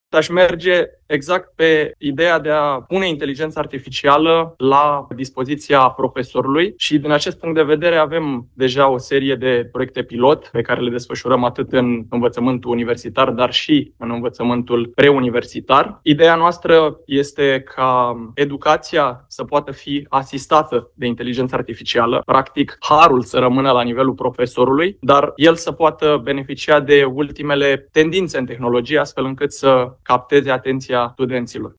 în conferința „Educația în era Inteligenței Artificiale” ținută la Parlament